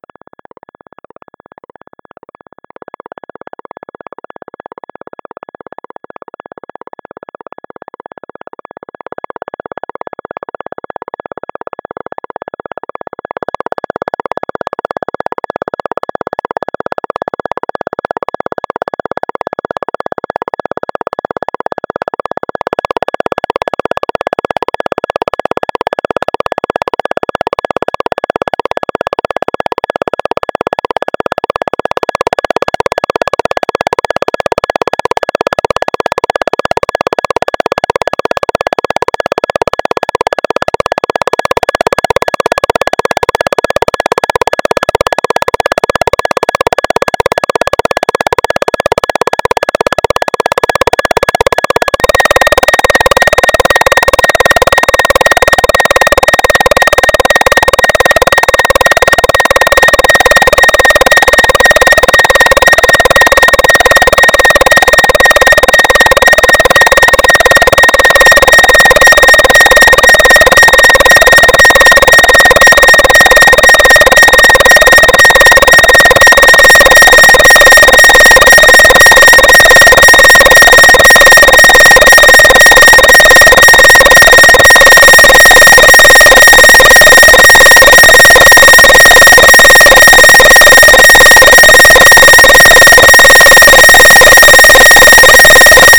This is one of my sounds created in the studio.
comet-imitation-sound.mp3